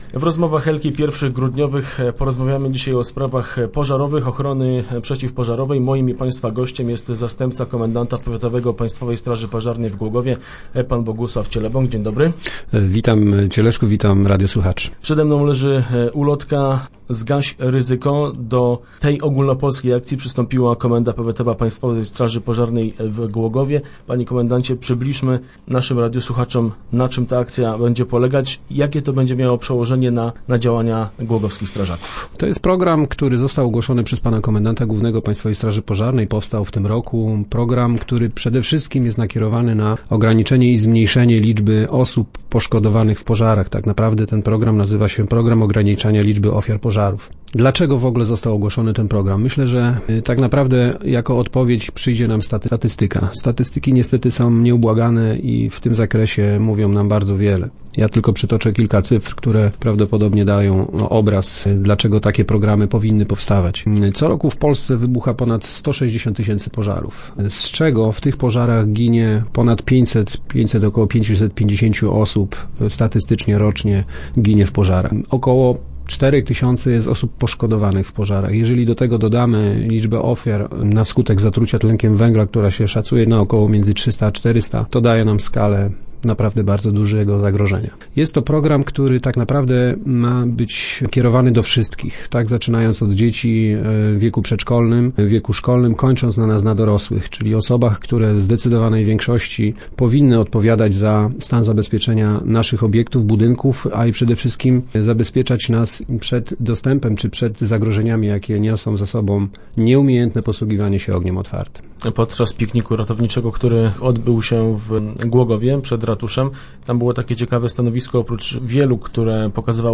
Start arrow Rozmowy Elki arrow Zgaś ryzyko